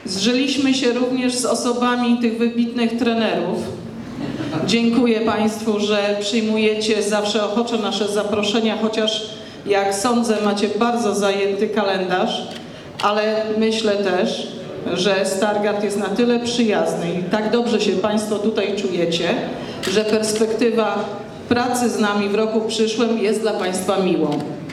Iwona Wiśniewska koncert finałowy.mp3